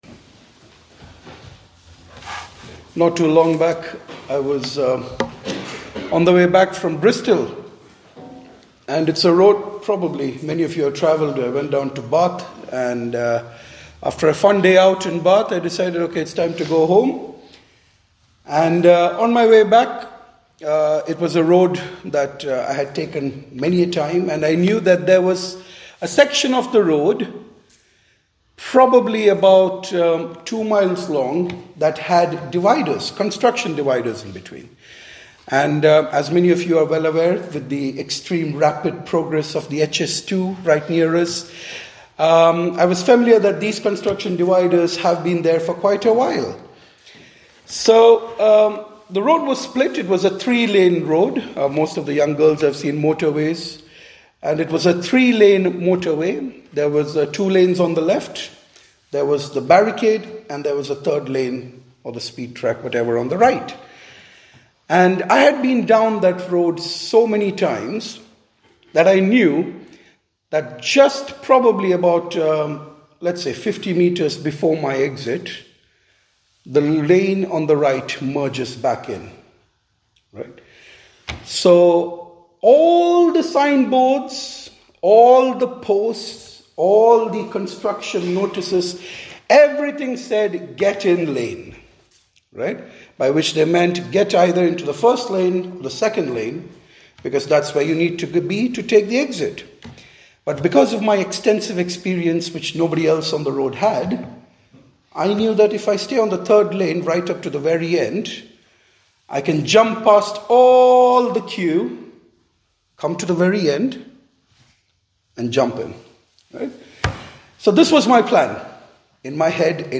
In this sermon